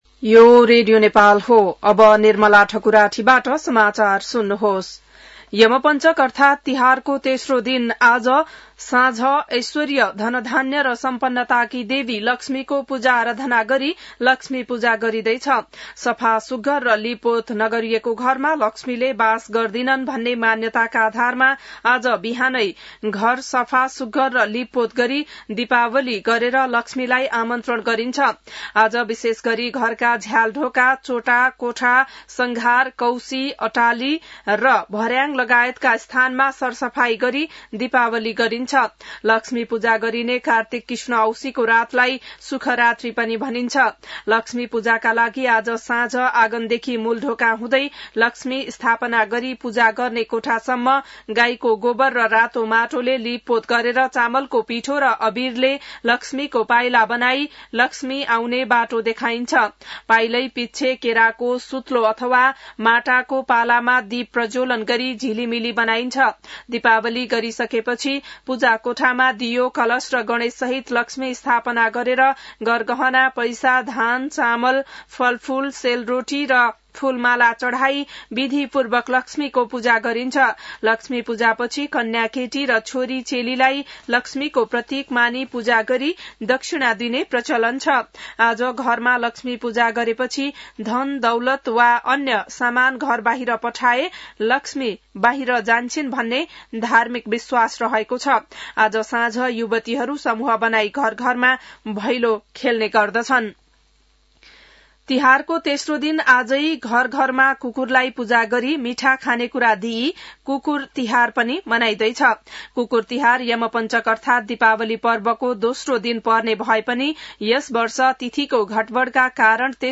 An online outlet of Nepal's national radio broadcaster
बिहान १० बजेको नेपाली समाचार : ३ कार्तिक , २०८२